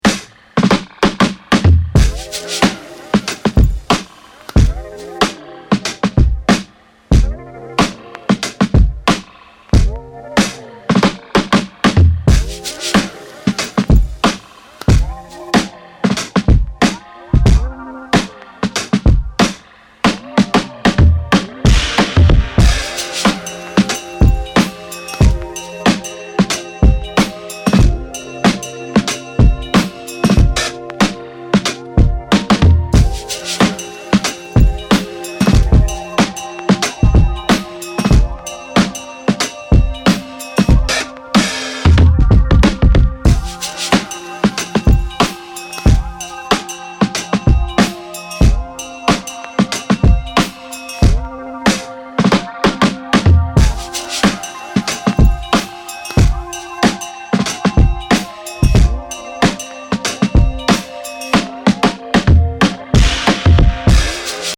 R&B, Soul, 90s
F min